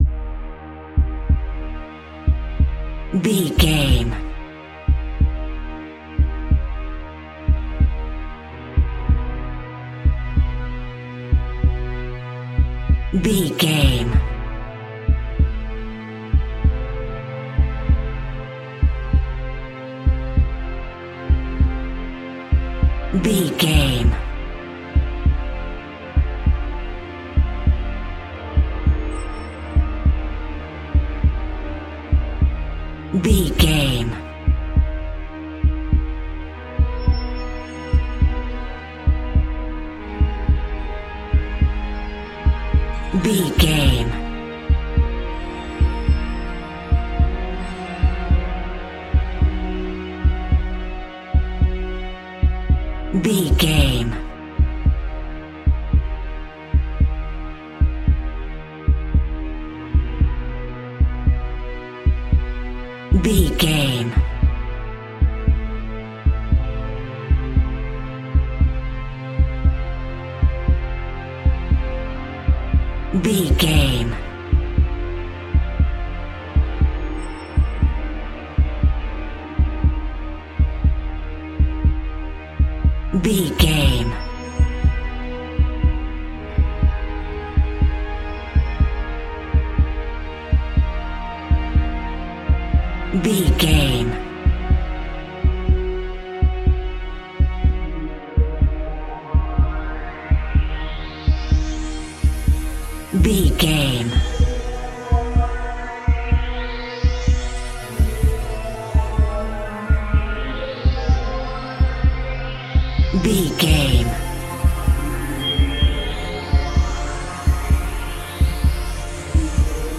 Aeolian/Minor
E♭
Slow
scary
tension
ominous
dark
suspense
haunting
eerie
strings
synthesiser
horror
pads
eletronic